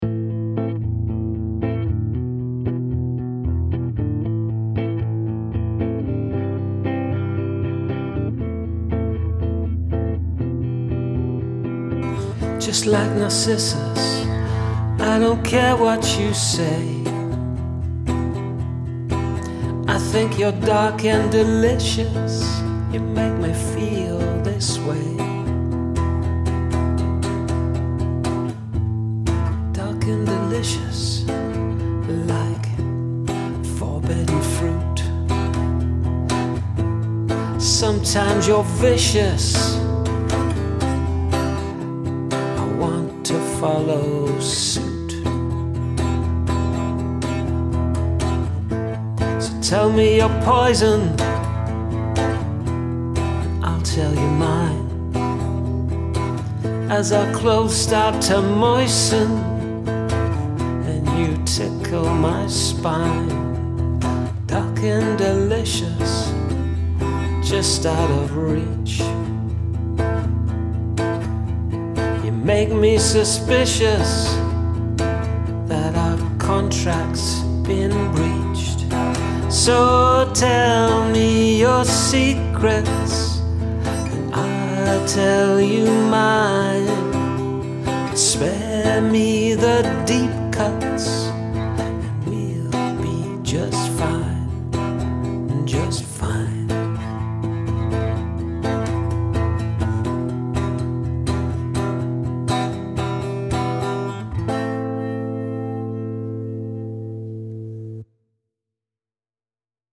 I like the vocal delivery, rhymes and whole feel of the song.